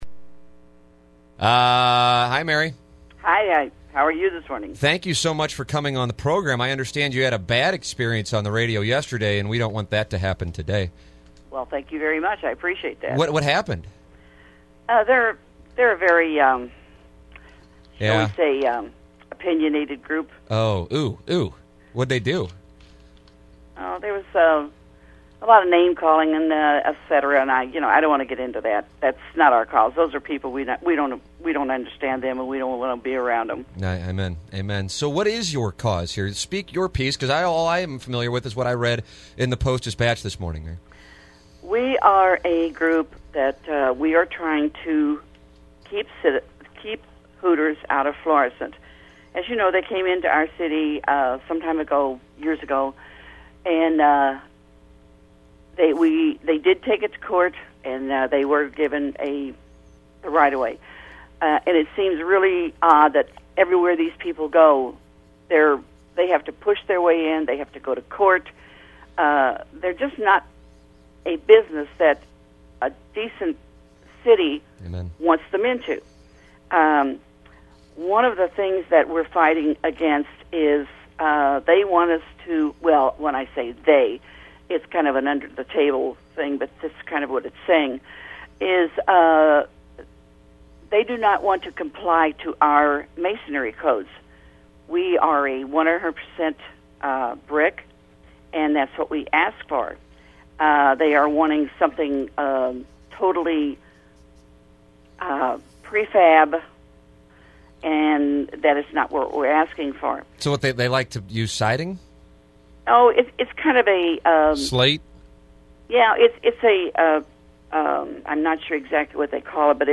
Interview-2-528_HootersInFlorissant.mp3